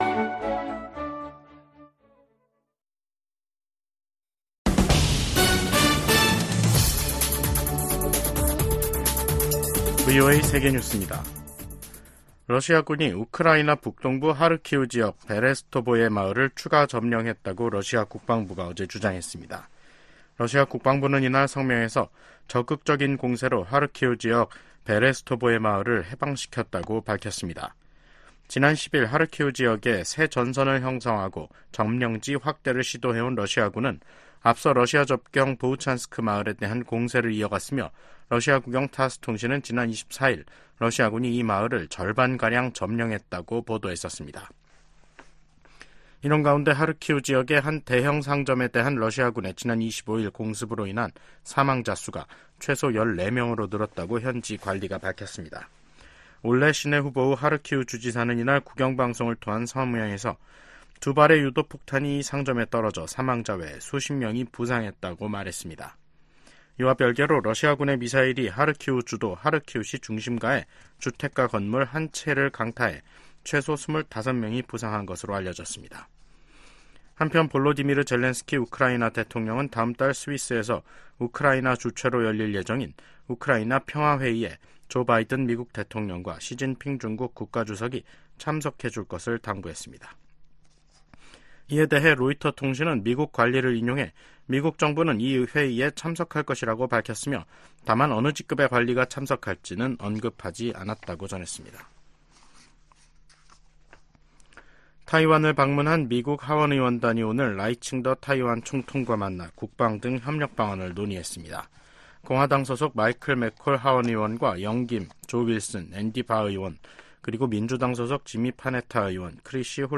VOA 한국어 간판 뉴스 프로그램 '뉴스 투데이', 2024년 5월 27일 2부 방송입니다. 한국과 일본, 중국 세 나라 서울에서 정상회의를 열고 북한 문제를 비롯한 3국 현안을 논의했습니다. 일본 항공국이 북한의 위성 발사가 예상된다며 자국 인근 상공을 지나는 항공기에 주의를 당부하는 항공고시보를 발행했습니다.